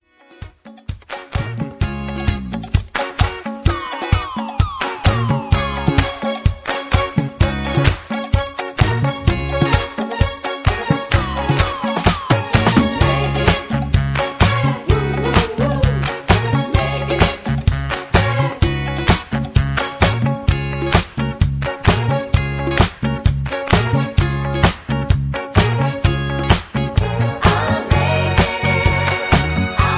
karaoke, mp3 backing tracks
rock, r and b, rap, rock and roll